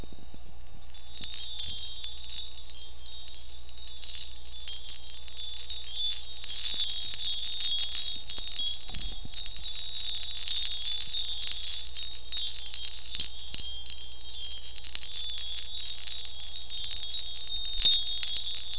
Specialty Antique Glass Wind Chimes & Baby Chimes
All chimes seen on this page are made with authentic antique Fostoria glass & antique silverplate.
chimessound1.wav